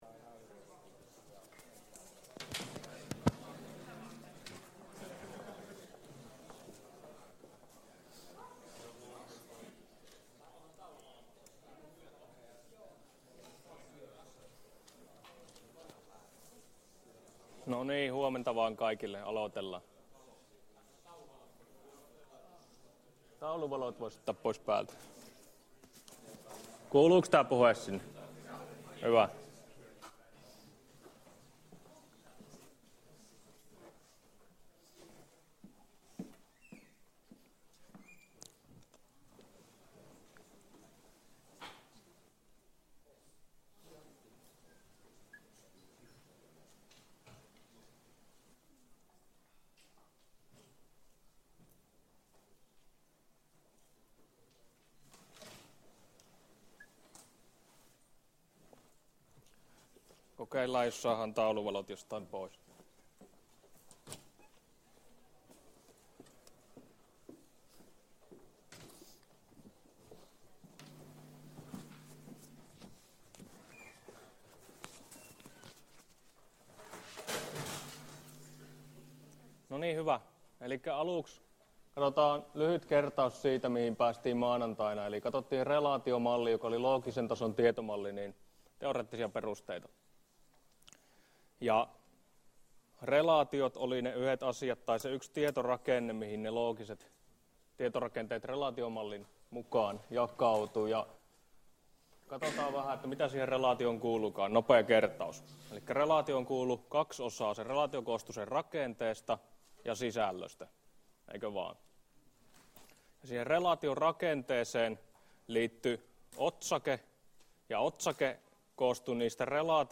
Luento 4 — Moniviestin